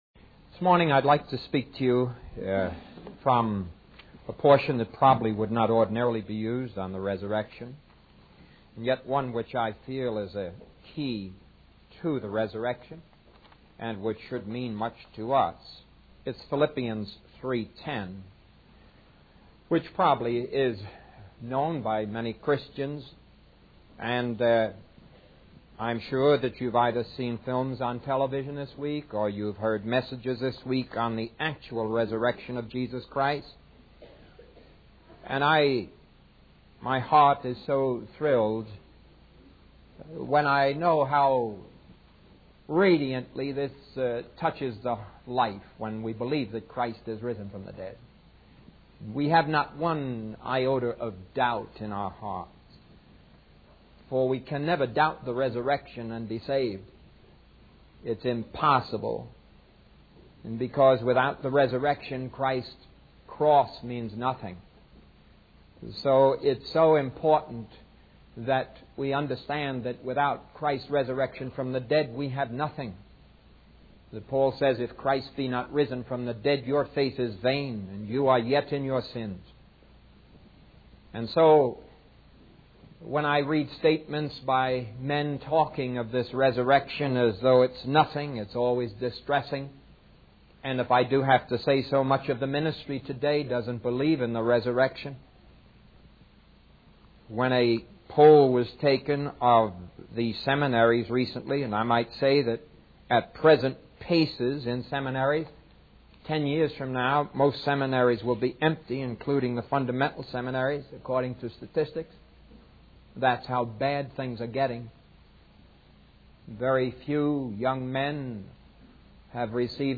In this sermon, the preacher emphasizes the need for individuals to trust in Christ as their Savior and believe in Him with all their hearts. He highlights the power of the resurrection and how it can bring victory and freedom from sin and death. The preacher encourages the congregation to set their affections on things above and to spend time with Jesus, treating Him as a living presence in their lives.